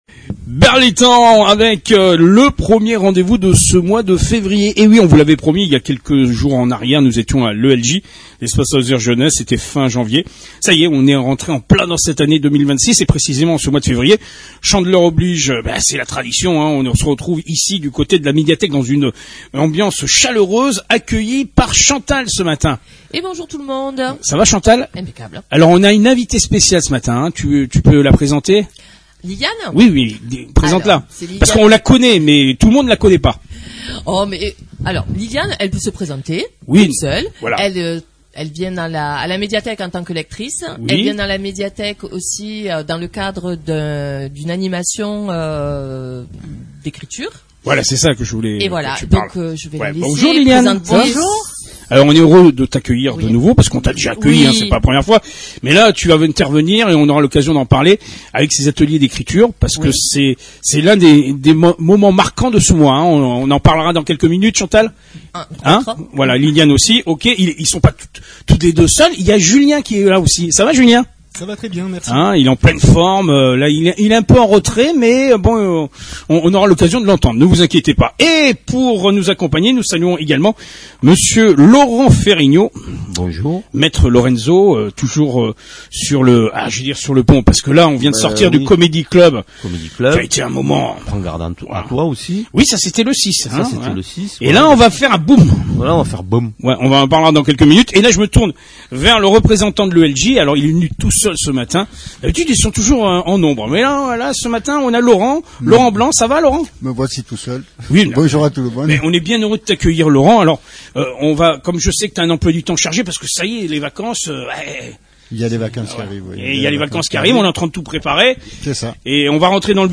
Branchez les micros, ça déménage !